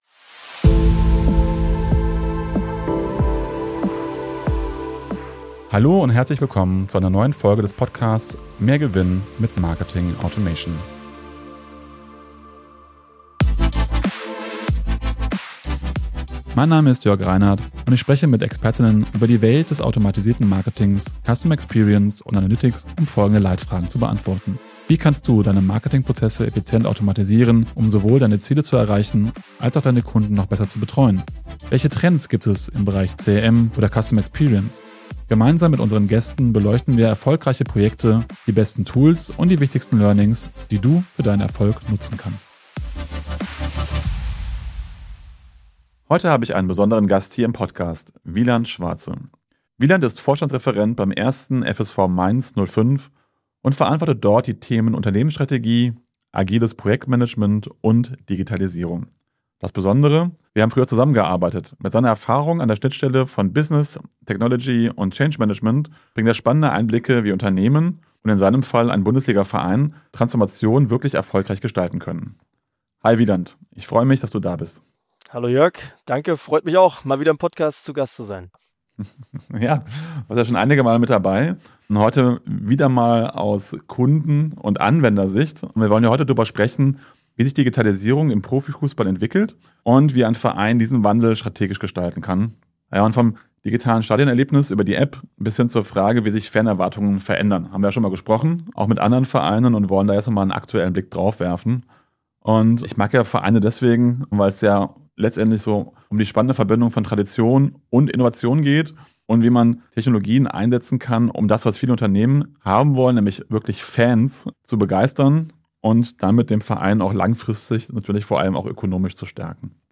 Wie aber gelingt der Spagat zwischen Fankultur und digitaler Innovation? Im Gespräch